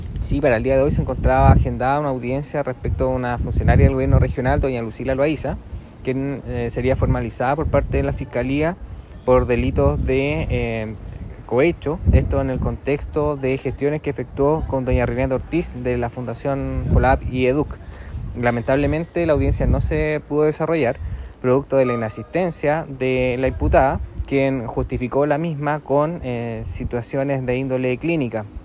El fiscal Carlos Cornejo, quien investiga el destino que tuvieron 730 millones de pesos traspasados desde el Gobierno Regional a las fundaciones Folab y Educc, dijo que es la cuarta vez que se suspende esta audiencia.
cu-fiscal-carlos-cornejo.mp3